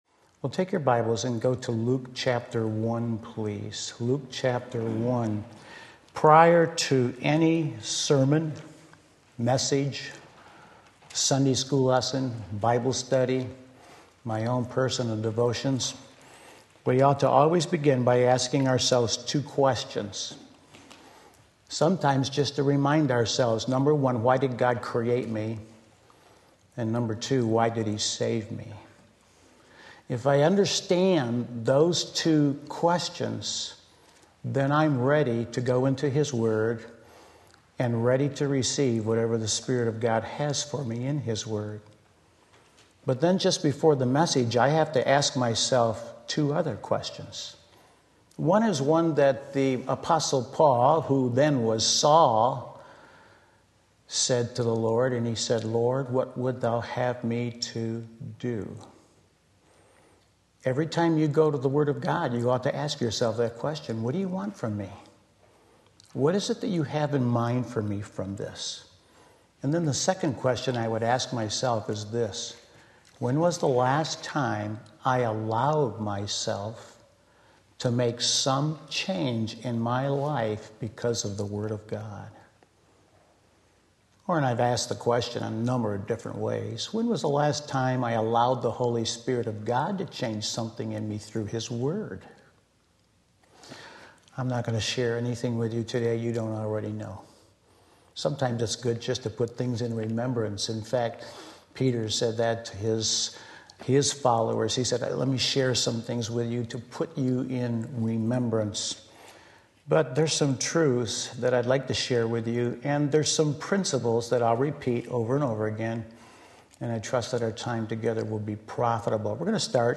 Delayed Prayer Luke 1:5-13 Sunday Afternoon Service